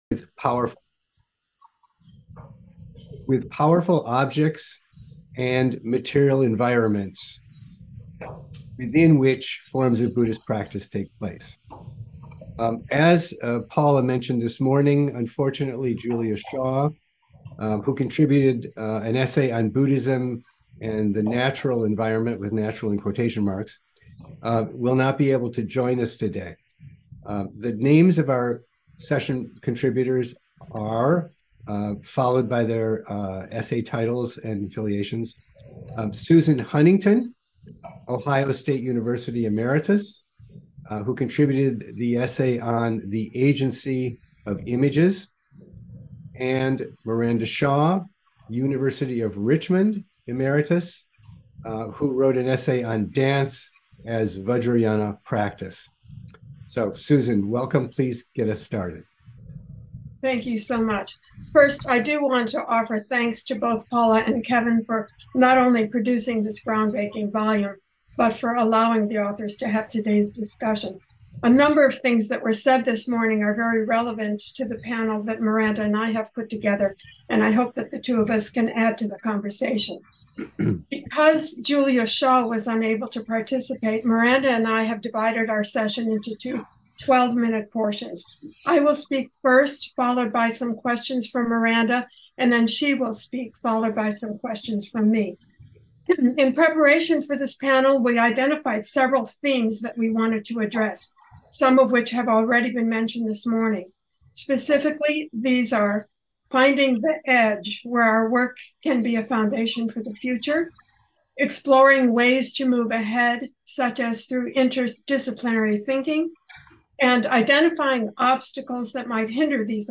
Each panelist presented a paper on their chapter in the anthology, followed by a moderated discussion and then question and answers.